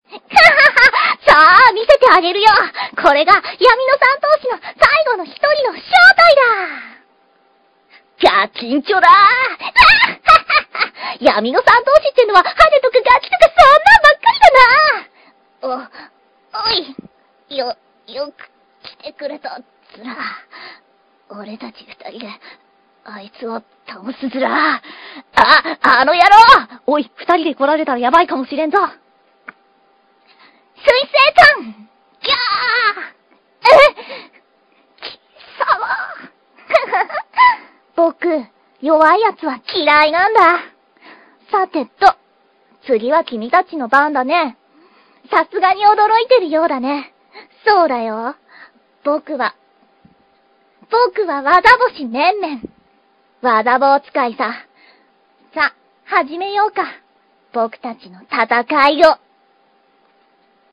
（出だしの音量が大きめなのでご注意ください）